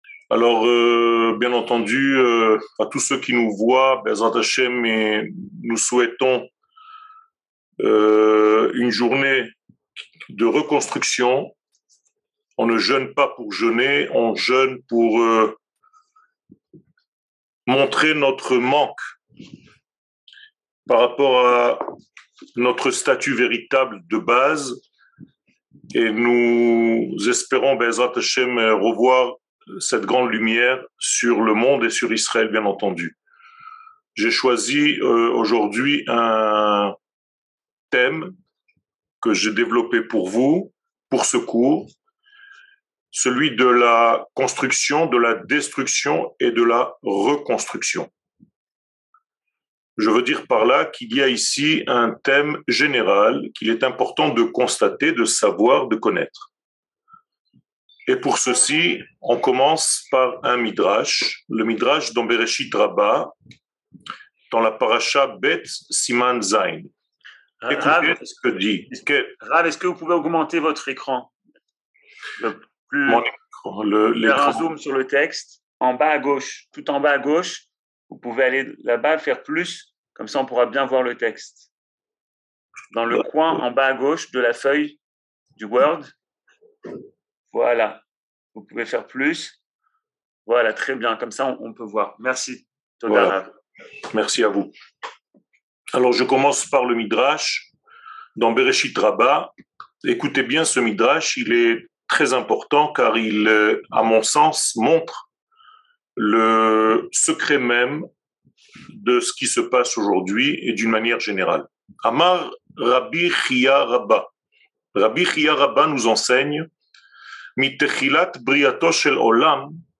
Destruction et construction ! replay du cours du 9 Av